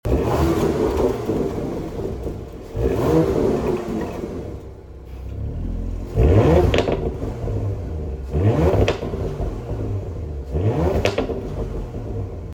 Listen to the Macan V6 Symphony
• 2.9-litre V6 Twin-Turbo Petrol Engine: 440PS & 550NM (169mph Top-Speed)
porsche-macan-gts-turbo-carrara-white-lvb_Sound-Clip.mp3